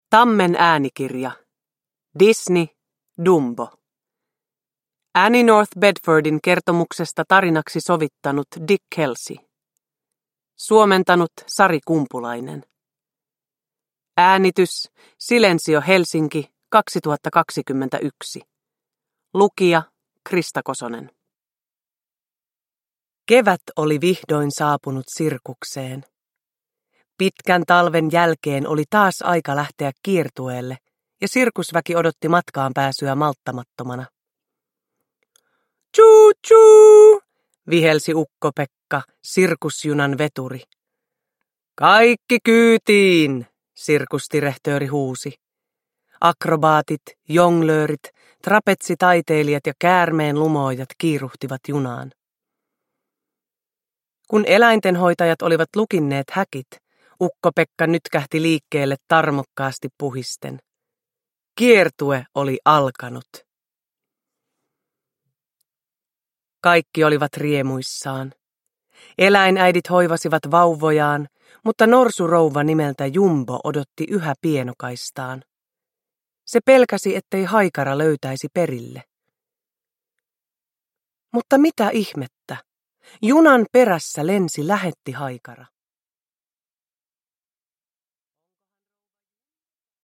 Dumbo – Ljudbok
Uppläsare: Krista Kosonen